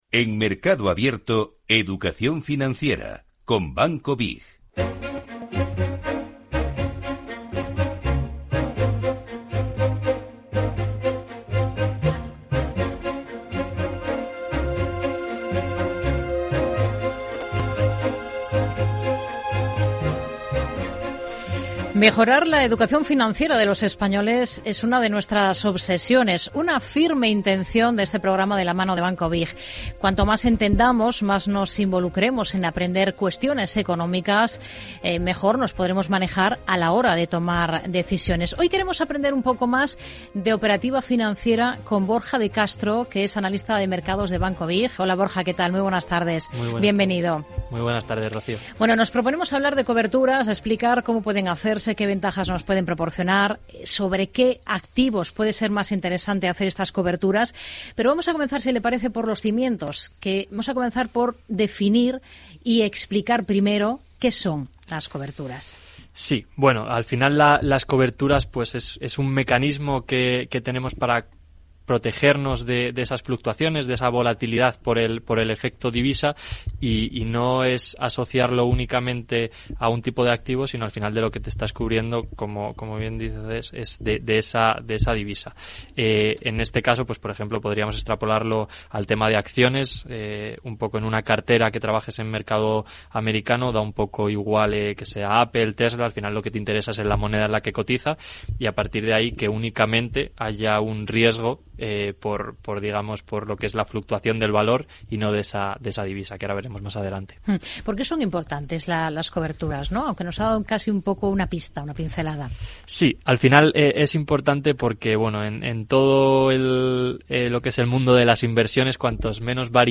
Banco BiG participa en el espacio de Educación Financiera de Capital Radio.